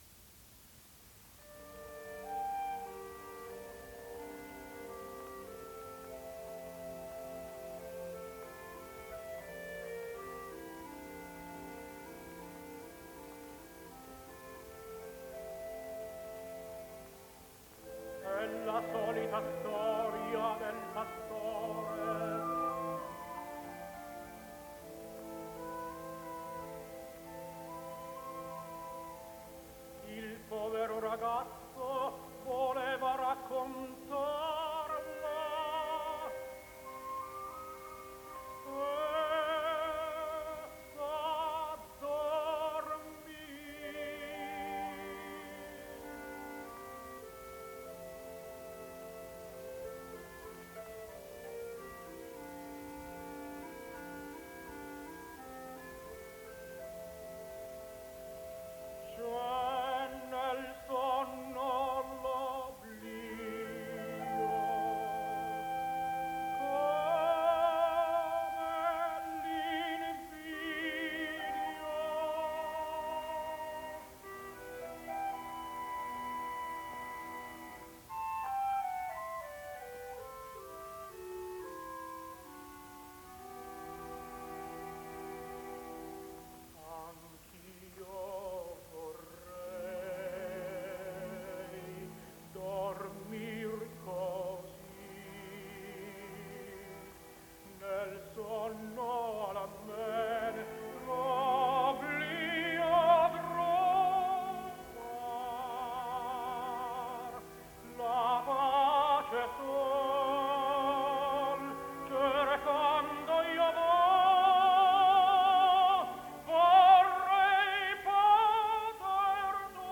Tenore TITO SCHIPA “L’emozione”